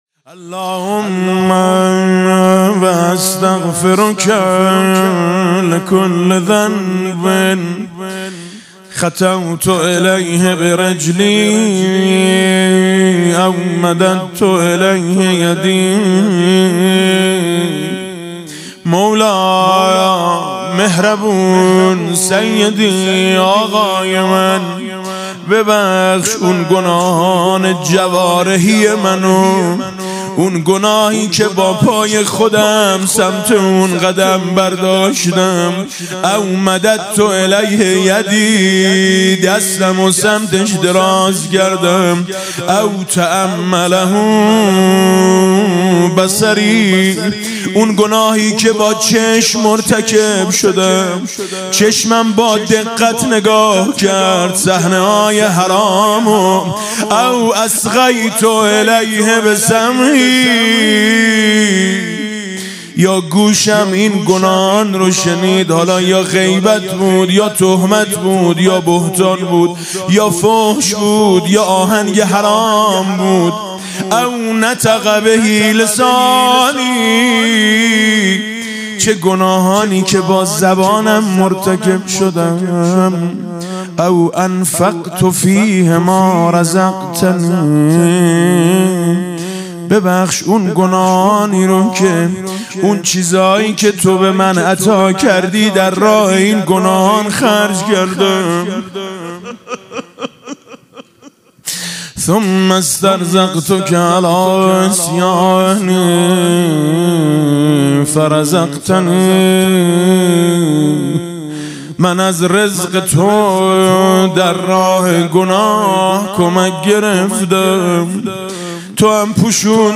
قرائت استغفار ۷۰ بندی حضرت امام علی علیه السلام با نوای دلنشین حاج میثم مطیعی